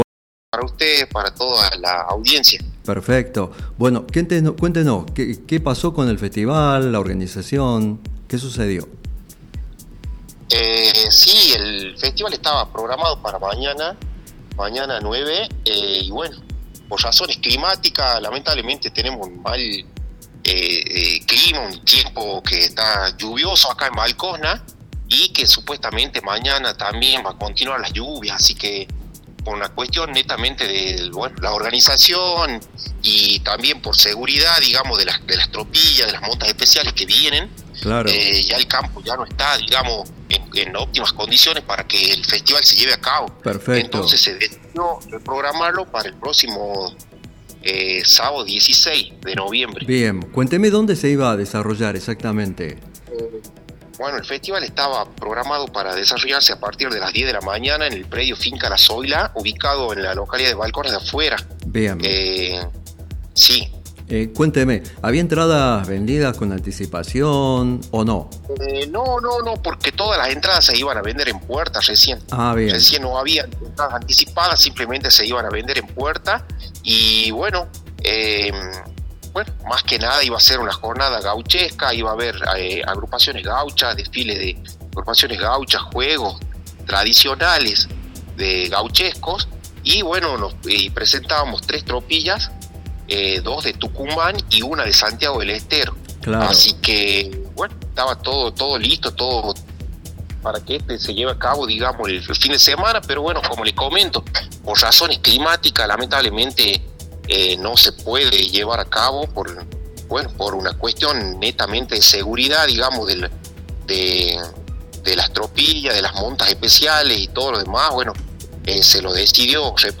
Entrevistas City